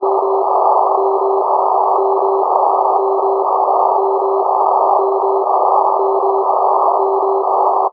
40_mhz_in_cw.wav